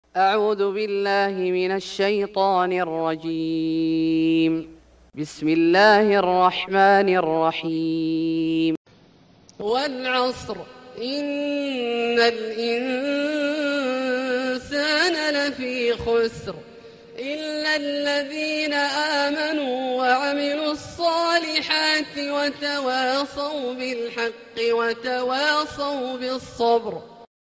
سورة العصر Surat Al-Asr > مصحف الشيخ عبدالله الجهني من الحرم المكي > المصحف - تلاوات الحرمين